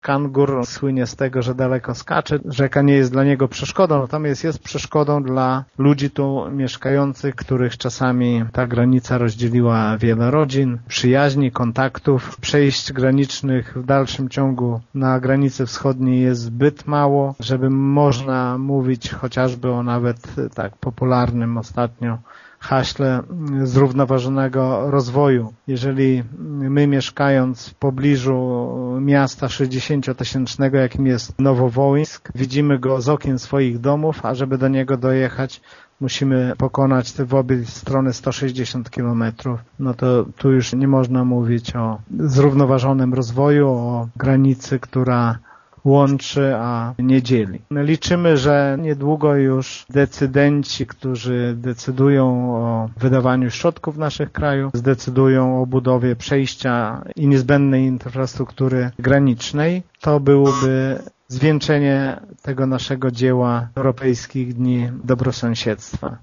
Wójt gminy Mircze Lech Szopiński tłumaczy, że wybór kangura na symbol pokonywania różnych trudności w kontaktach mieszkańców terenów przygranicznych po obydwu stronach Bugu, nie jest przypadkowy: